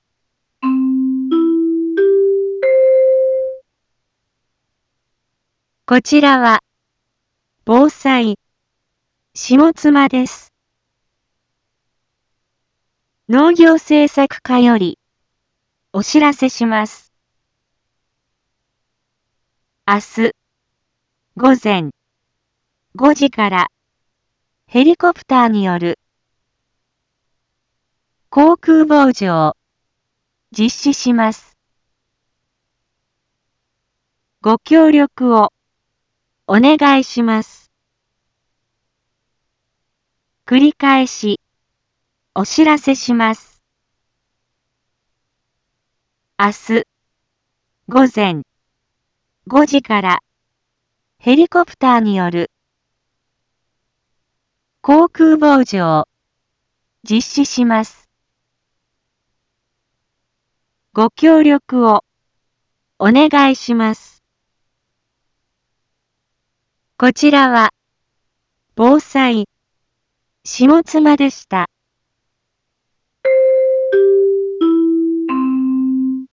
一般放送情報
Back Home 一般放送情報 音声放送 再生 一般放送情報 登録日時：2023-07-24 12:31:18 タイトル：農林航空防除について インフォメーション：こちらは、防災、下妻です。